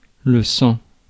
Ääntäminen
Ääntäminen France: IPA: /sɑ̃/ Haettu sana löytyi näillä lähdekielillä: ranska Käännös Konteksti Ääninäyte Substantiivit 1. blood anatomia, kuvaannollinen US UK 2. gore Suku: m .